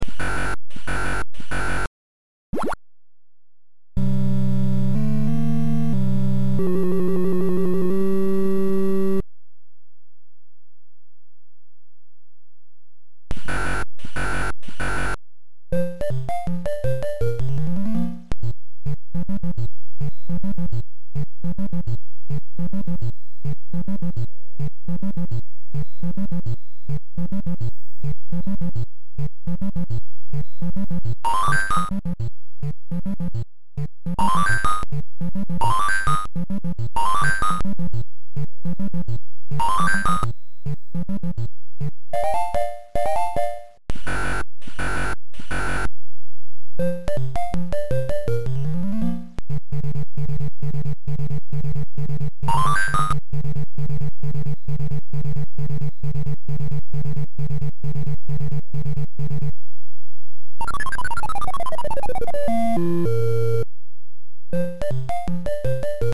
this starts already with the "donkey kong smile" sample  ???  and misses the walking sounds